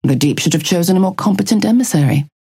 Calico voice line - The Deep should have chosen a more competent emissary.